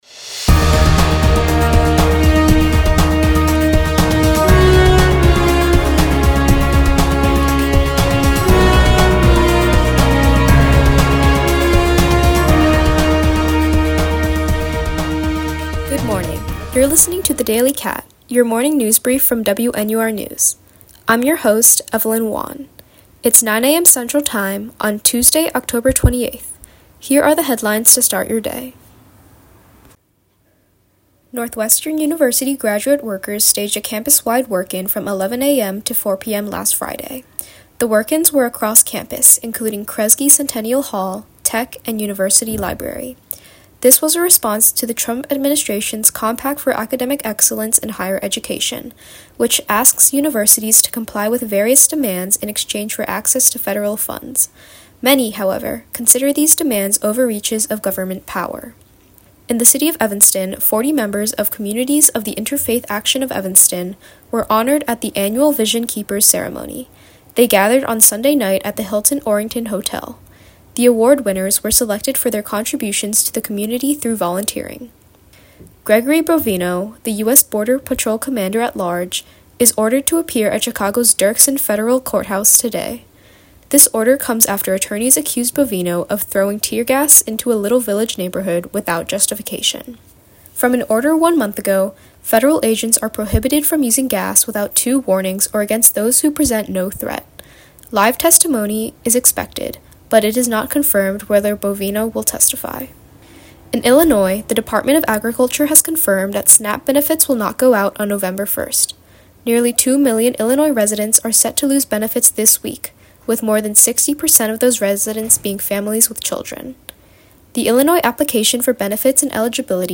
October 28, 2025: Graduate work-in, Interfaith Action of Evanston, Gregory Bovino in court, SNAP benefits not out, Sami Hamdi, Hurricane Melissa. WNUR News broadcasts live at 6 pm CST on Mondays, Wednesdays, and Fridays on WNUR 89.3 FM.